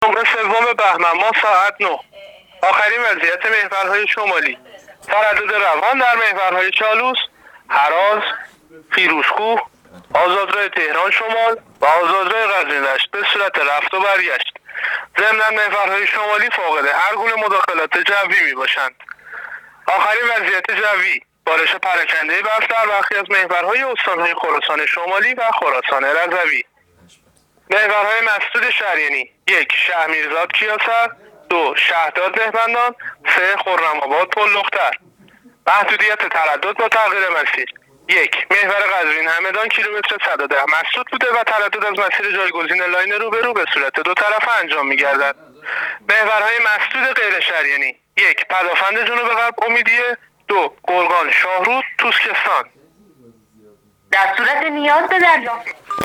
گزارش رادیو اینترنتی از آخرین وضعیت ترافیکی جاده‌ها تا ساعت ۹ سوم بهمن